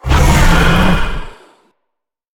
Sfx_creature_hiddencroc_flinch_04.ogg